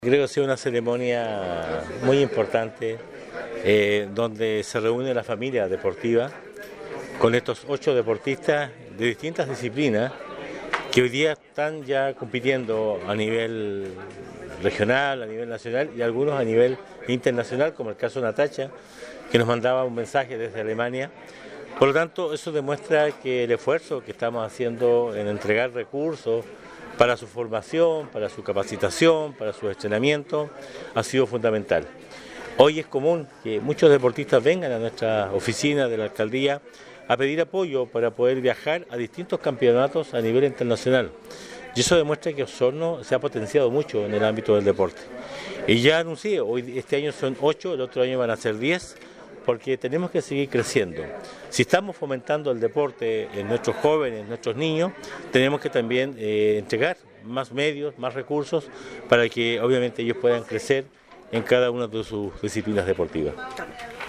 El alcalde Emeterio Carrillo destacó, además, el aumento de 6 a 8 ganadores que tuvo esta beca para este año, “lo que responde a nuestro compromiso por apoyar a más deportistas a cumplir su sueño de ser profesionales., y por ello el próximo año se aumentará a 10 deportistas becados»